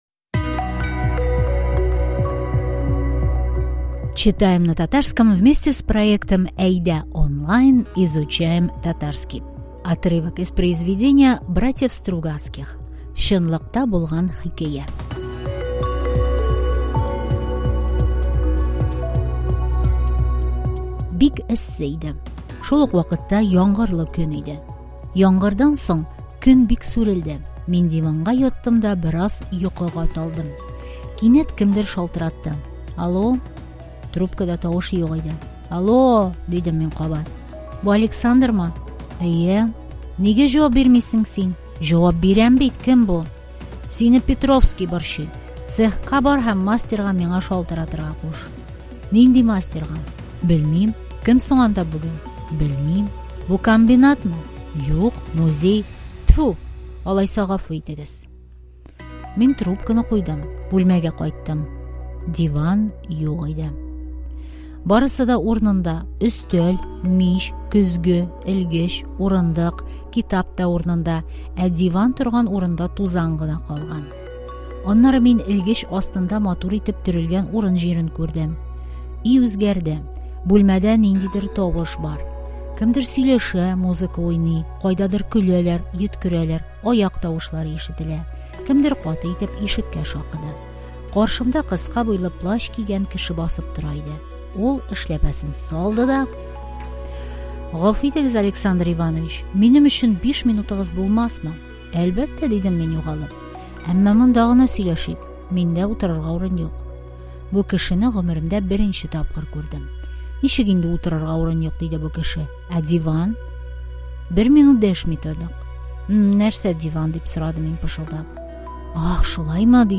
читаем на татарском